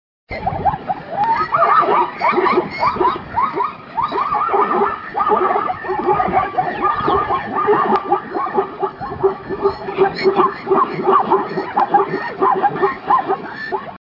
Zebras on the move.
Zebra.mp3